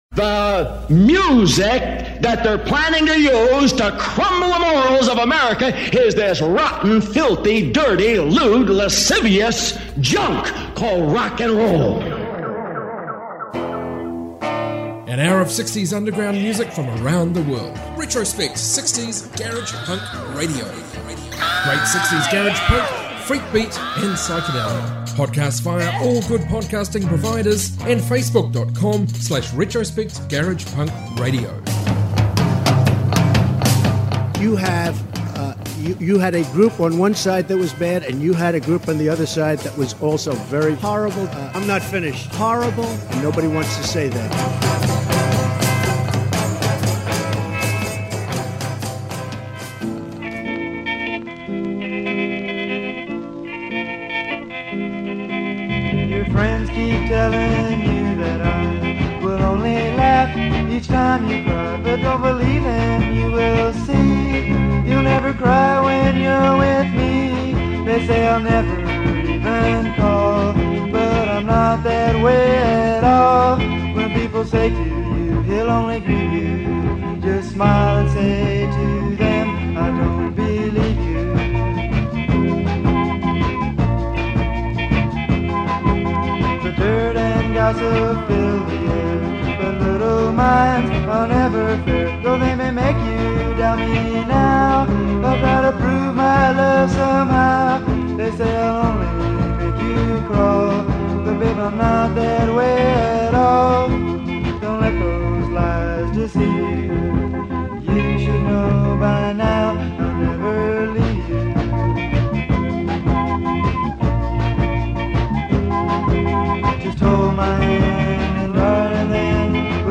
60's global garage